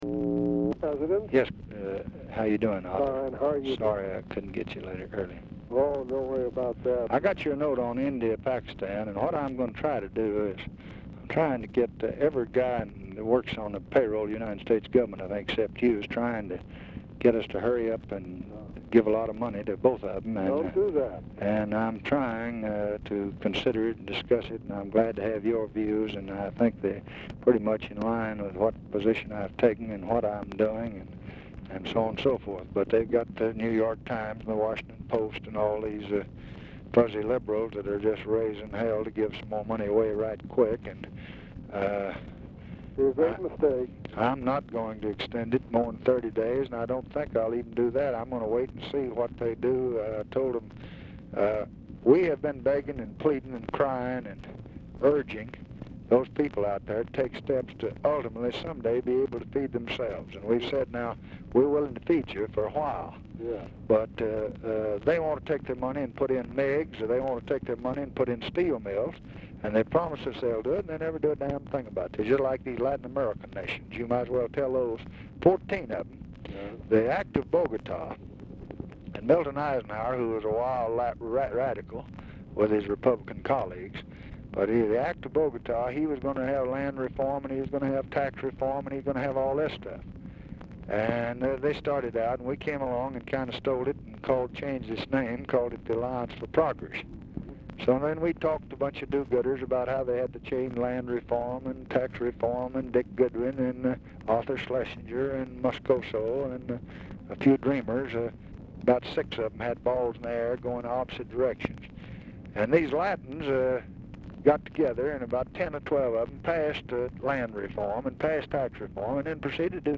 Telephone conversation # 9028, sound recording, LBJ and ARTHUR GOLDBERG, 10/22/1965, 3:30PM | Discover LBJ
Format Dictation belt
Location Of Speaker 1 Oval Office or unknown location